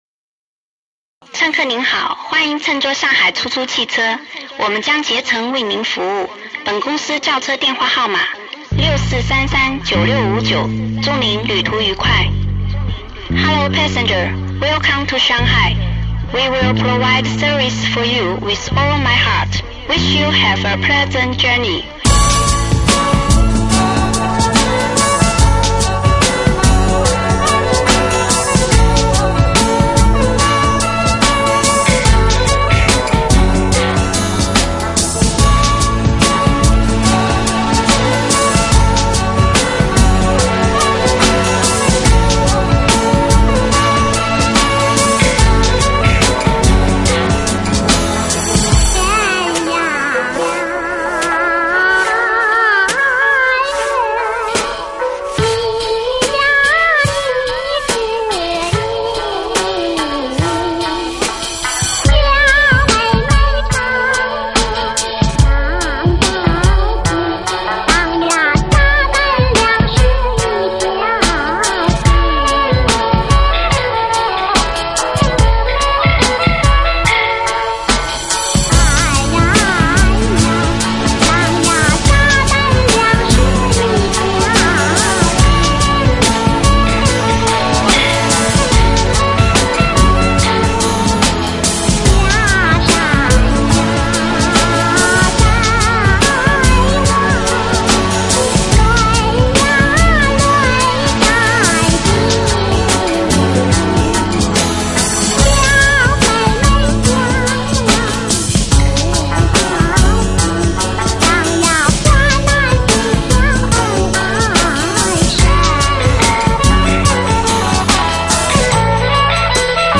听到上海出租车上空车牌翻起的时候放出的提示声音